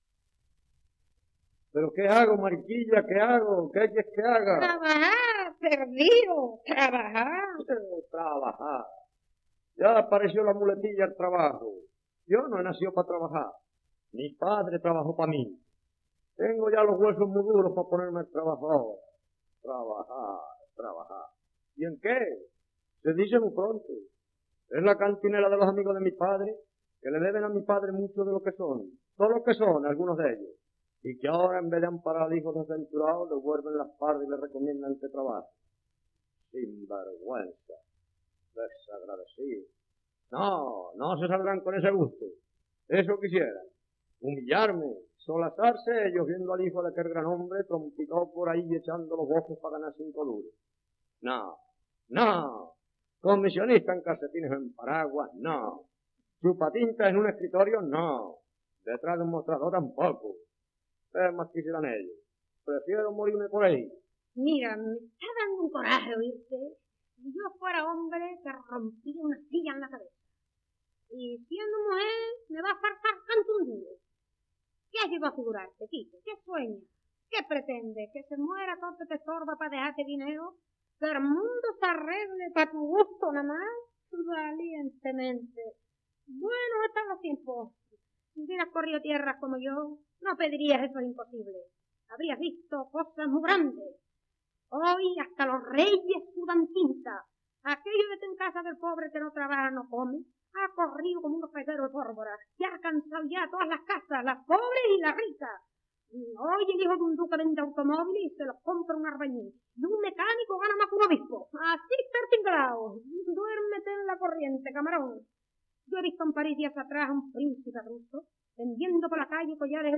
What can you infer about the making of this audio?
Mariquilla Terremoto. Acto II. Escena última (sonido remasterizado)